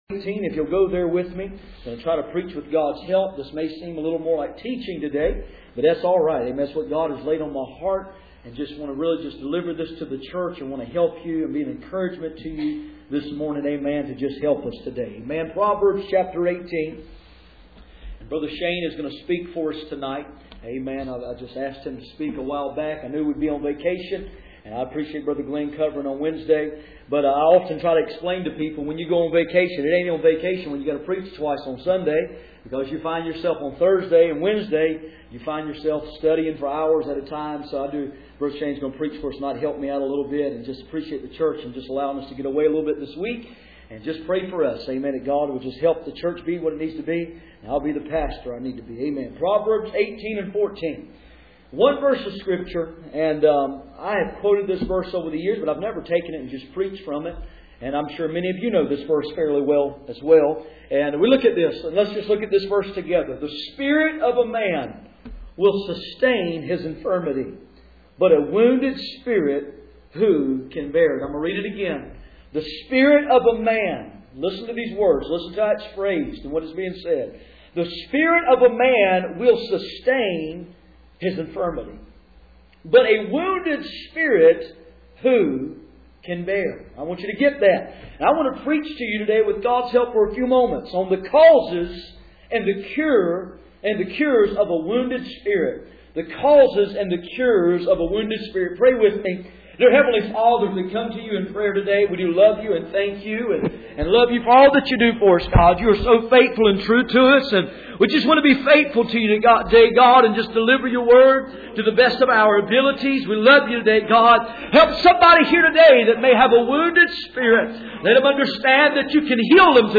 Passage: Proverbs 18:14 Service Type: Sunday Morning